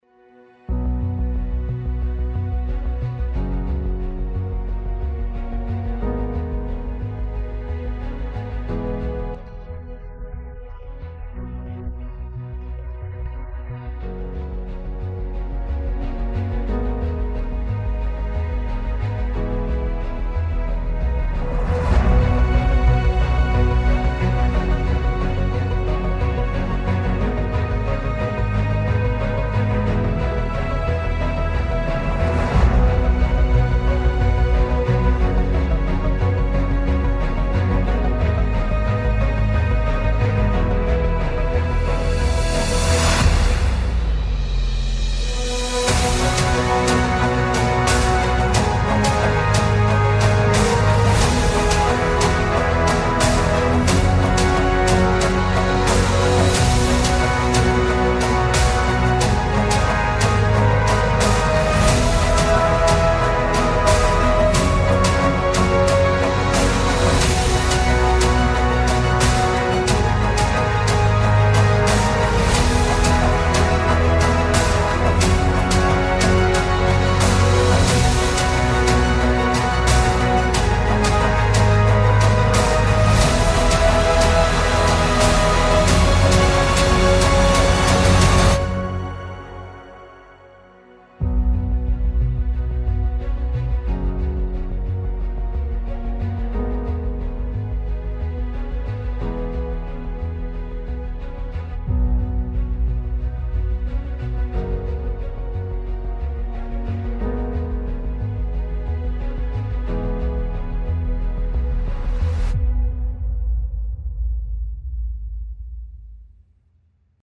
大气磅礴